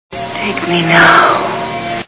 Ghostbusters Movie Sound Bites